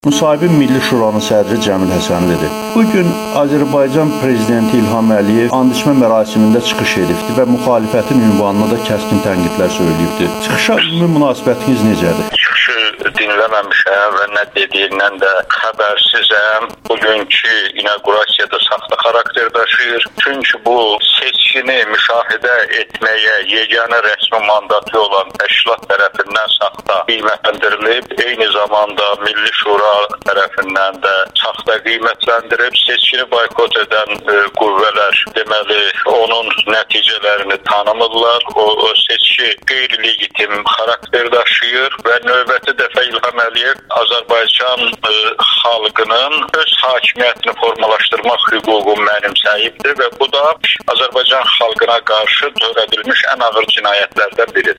İlham Əliyevin andiçmə çıxışı - müxtəlif münasibətlər [Audio-müsahibələr]
Milli Şuranın sədri Cəmil Həsənlinin Amerikanın Səsinə müsahibəsi